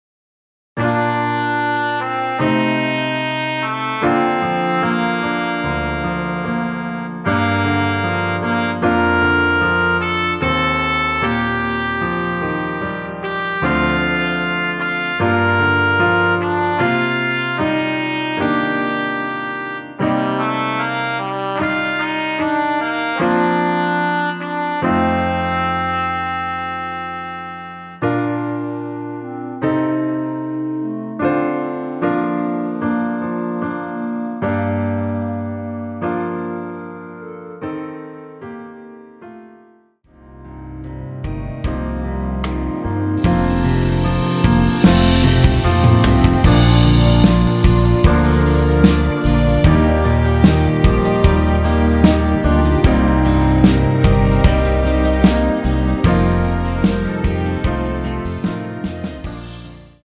발매일 1995.05 원키에서(-2) 내린 멜로디 포함된 MR 입니다.(미리듣기 참조)
Bb
멜로디 MR이라고 합니다.
앞부분30초, 뒷부분30초씩 편집해서 올려 드리고 있습니다.
중간에 음이 끈어지고 다시 나오는 이유는